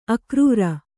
♪ akrūra